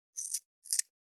508桂むき,大根の桂むきの音切る,包丁,厨房,台所,
効果音厨房/台所/レストラン/kitchen食材